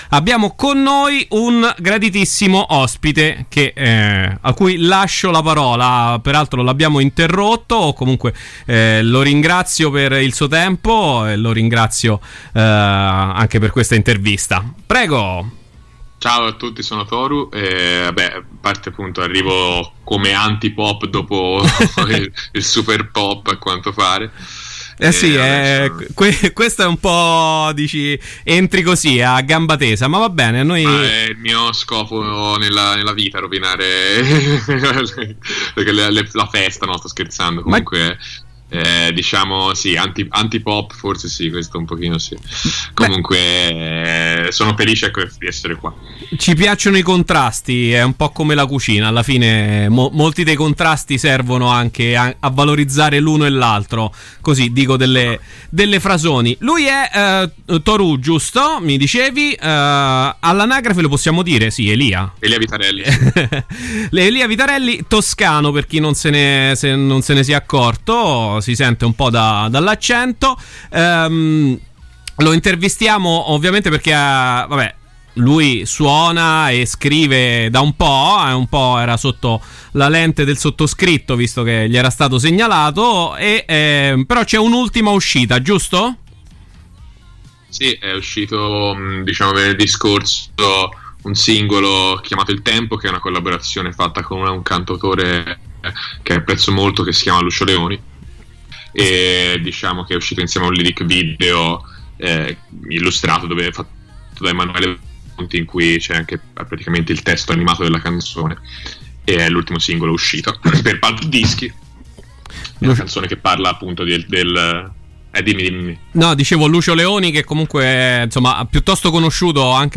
Una chiacchierata che ha parlato del suo album, delle influenze, delle conoscenze, dei live, del particolare momento che stiamo vivendo.